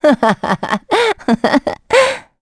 Gremory-Vox_Happy3.wav